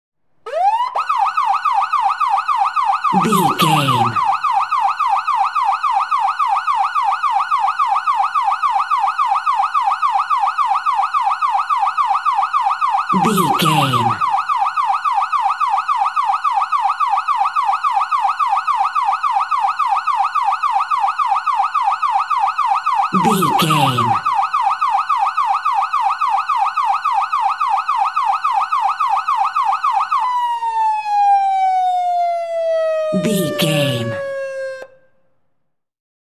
Ambulance Ext Short Siren 89
Sound Effects
urban
chaotic
dramatic